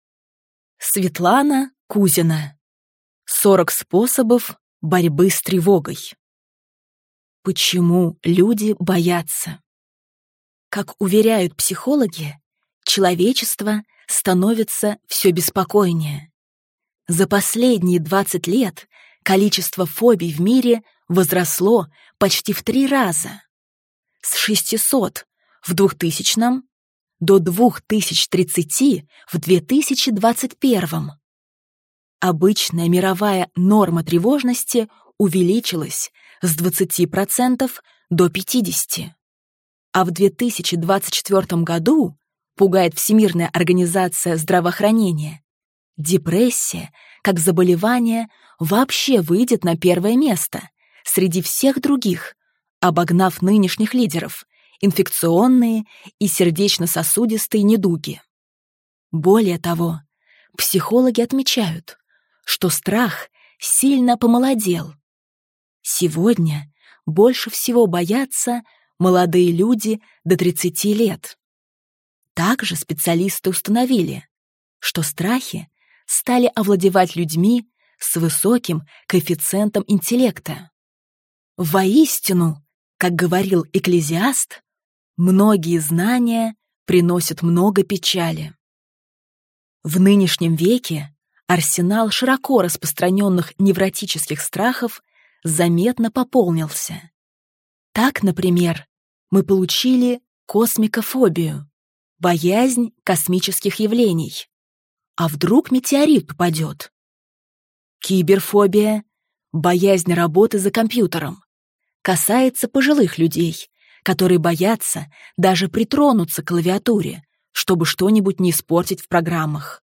Аудиокнига 40 способов борьбы с тревогой | Библиотека аудиокниг